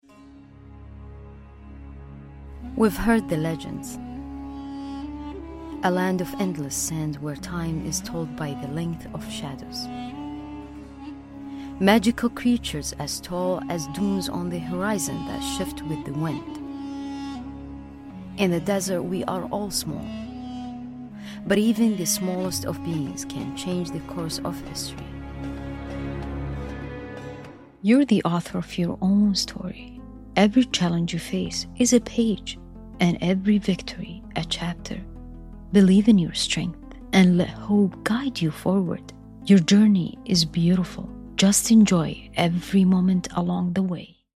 Arabic (Saudi Arabia), Middle Eastern, Female, Home Studio, 20s-40s, Based in LA